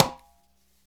Index of /90_sSampleCDs/Roland - Rhythm Section/PRC_Latin 1/PRC_Conga+Bongo